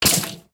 assets / minecraft / sounds / mob / stray / hurt2.ogg
hurt2.ogg